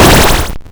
line clear.wav